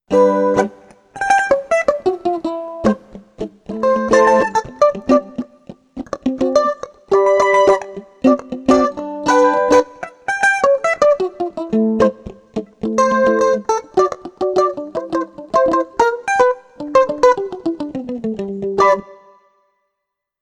Mandoline Electrique Harley Benton
Une mandoline électrique. C'est finalement l' instrument électrique le plus aigu que je possède. Il permet de faire des choses qu' on ne peut faire avec une guitare, cela est du autant à la tessiture, la façon d' accorder et le son des cordes doublées. Ici cela se veut ressembler à une stratocaster.....